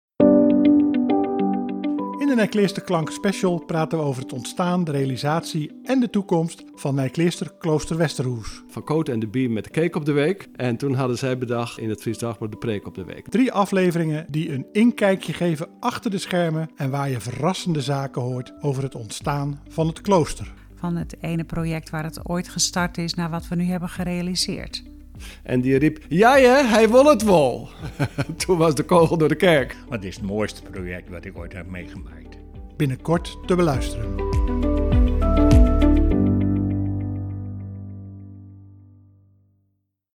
Promo Nijkleasterklank extra. Een serie van drie podcast over het ontstaan, realisatie en verdere ontwikkeling van Nijkleaster – Klooster Westerhûs.